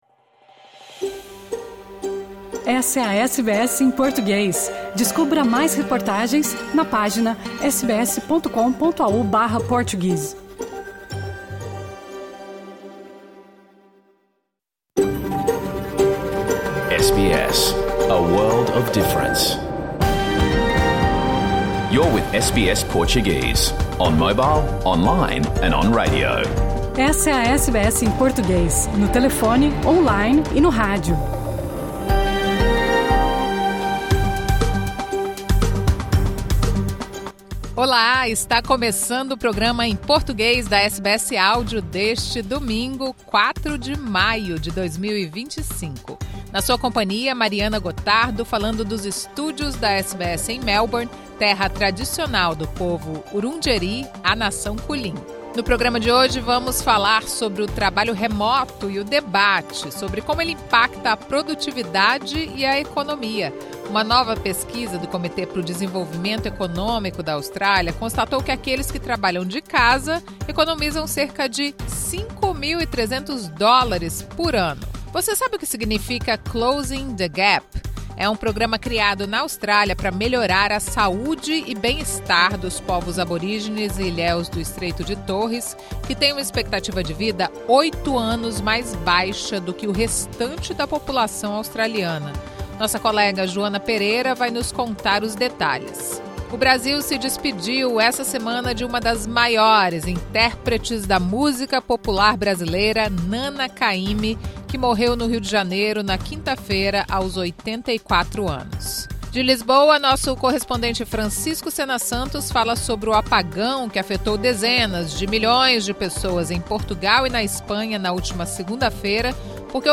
Programa ao vivo | Domingo 4 de maio